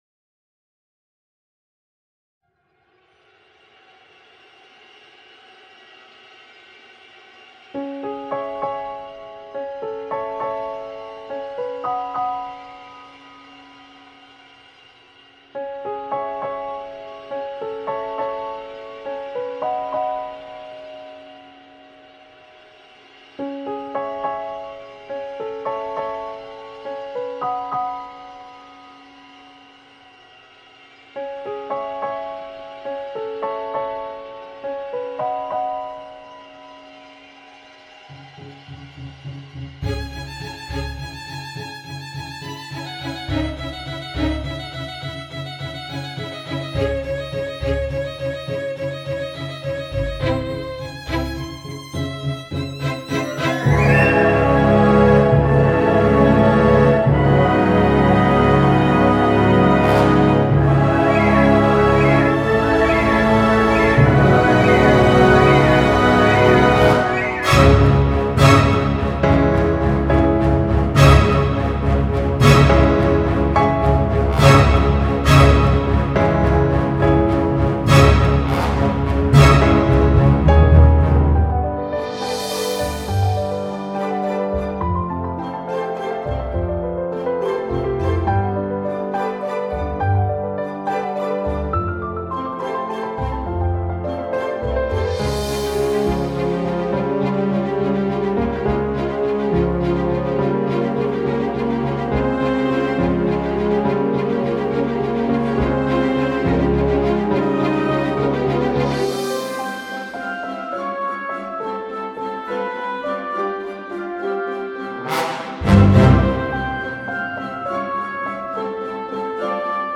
Electronic music inspired by space